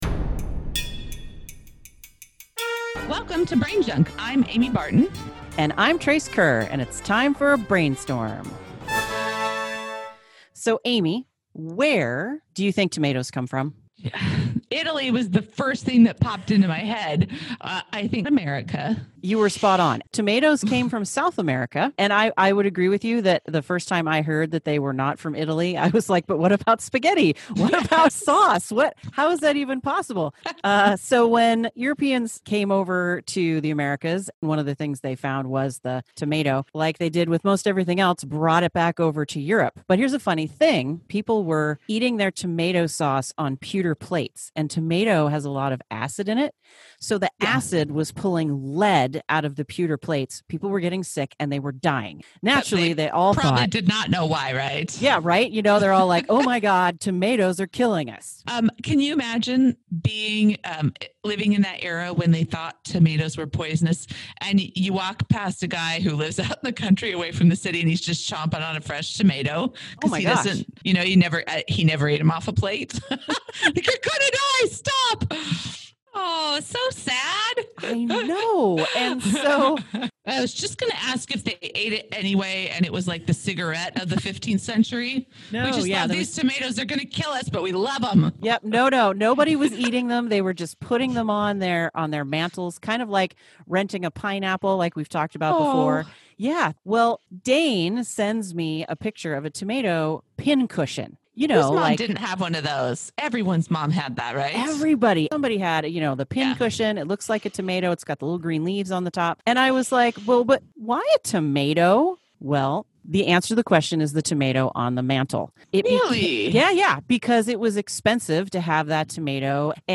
P.S. We had some recording issues--darn you COVID-19.